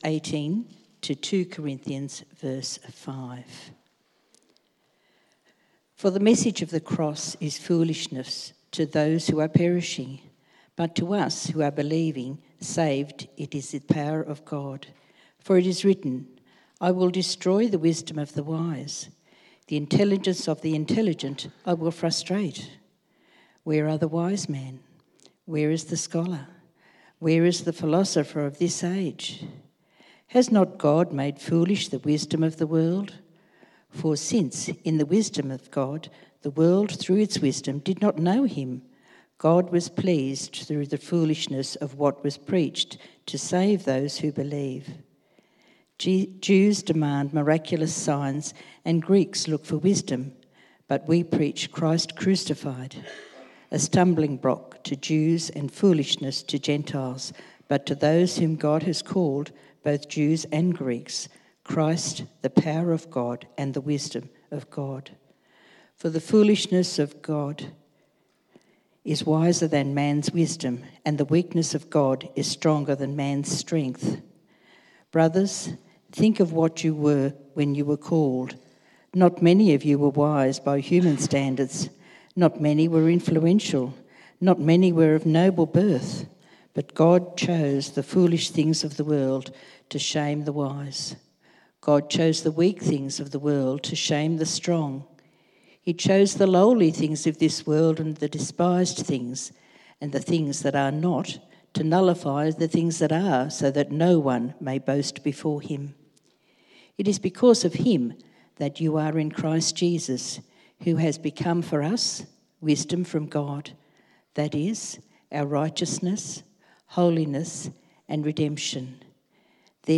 1 Corinthians 1:18-2:5 Service Type: AM In 1 Corinthians 1